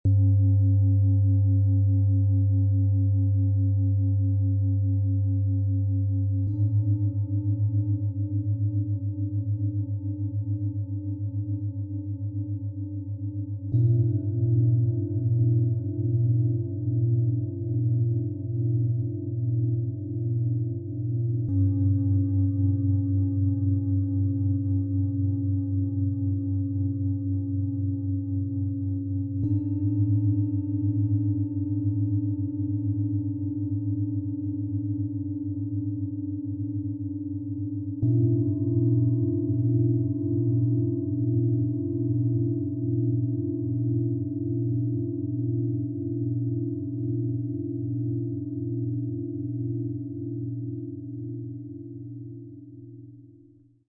Profi Klangmassage Set - tief entspannend, beruhigend, balancierend - Set aus 3 Klangschalen, Ø 22 - 25,5 cm, 4,01 kg
Mit ihrem tiefen Ton erzeugt sie eine kräftige Vibration, die tief ins Gewebe hineinwirkt.
Ihr etwas höherer Ton und die feinere Vibration wirken besonders angenehm im Kopfbereich.
Im Sound-Player - Jetzt reinhören können Sie den Original-Ton genau dieser professionellen Klangschalen anhören – tief, tragend und wohltuend für Körper und Geist.
Folgende ausgesuchte Klangschalen lassen das Set kraftvoll und ausgewogen erklingen:
MaterialBronze